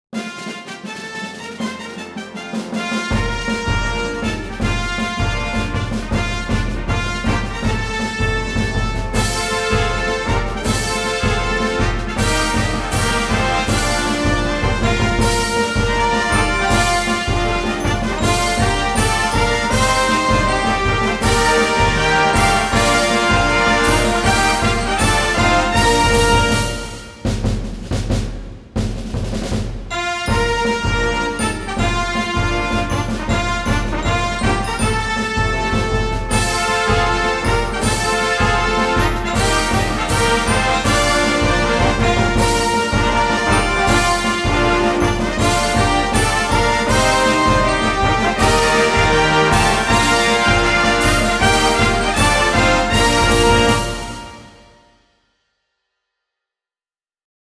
The Creighton University Fight Song
Creighton_Fight_Song.mp3